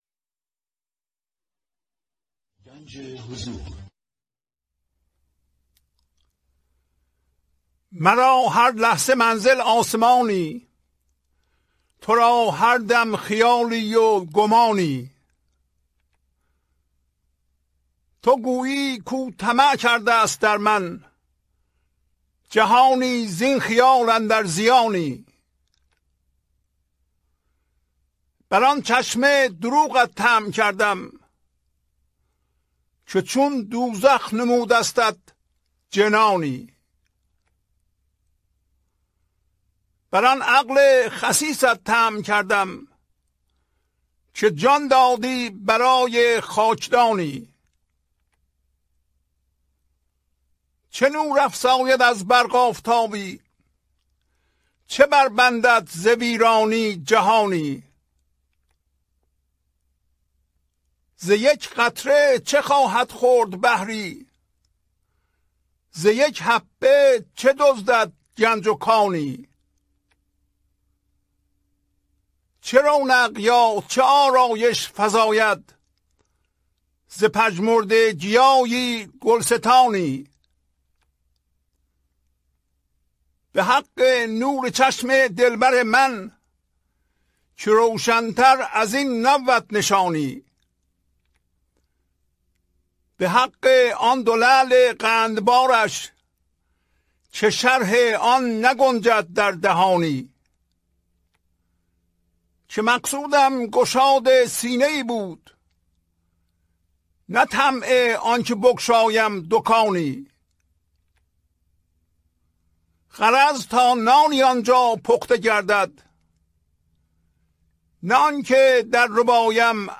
خوانش تمام ابیات این برنامه - فایل صوتی
1043-Poems-Voice.mp3